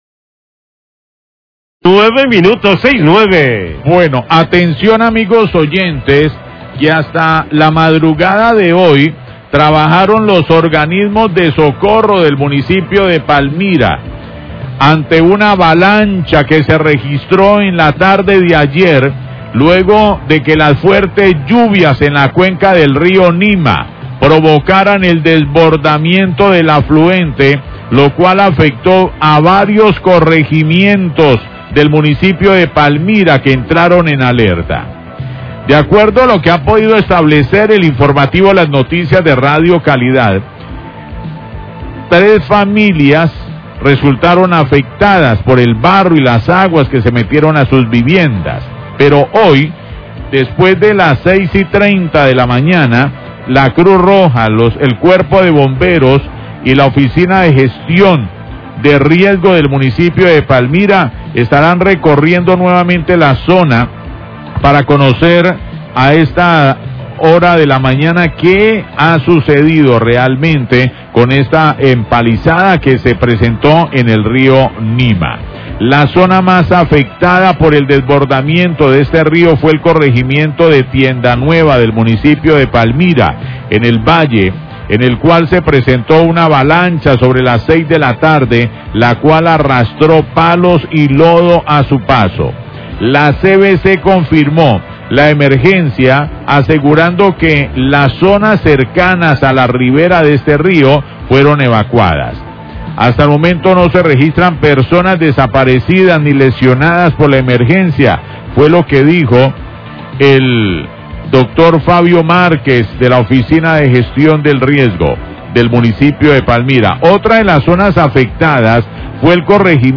NOTICIAS DE CALIDAD